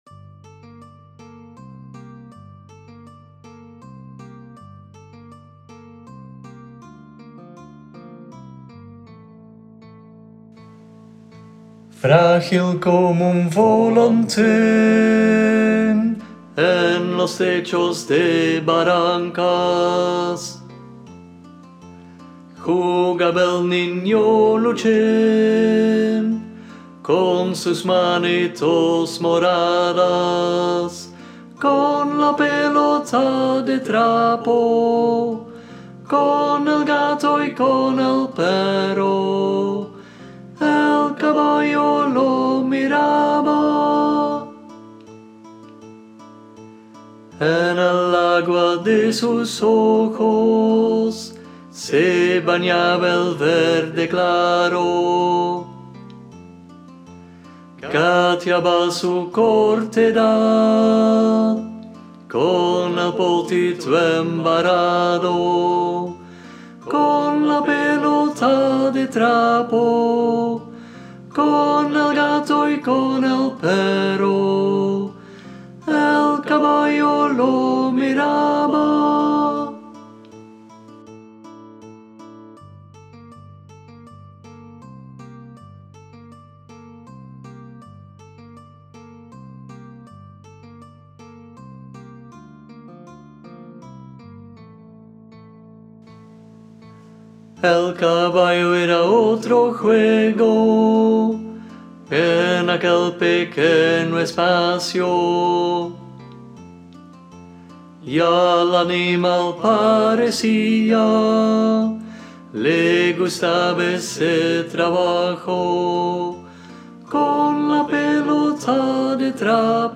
Luchín Ten.m4a